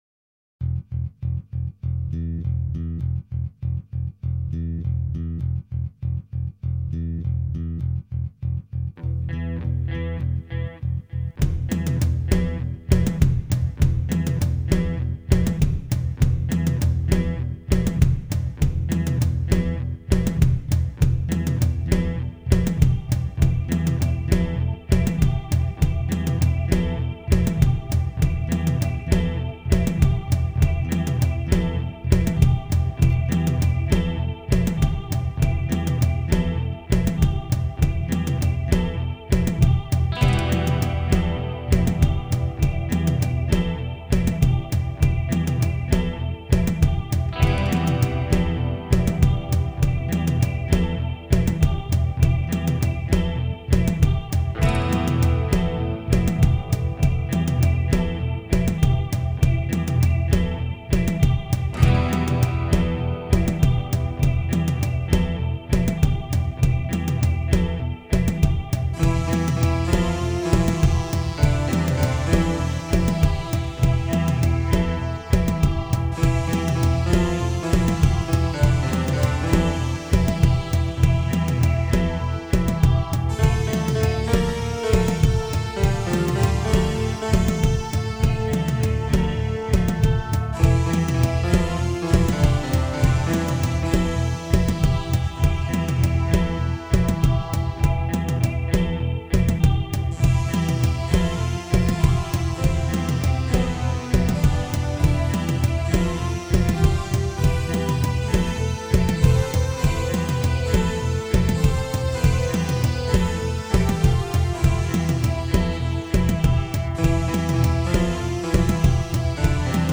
Lead Guitars/
Drums/Percussion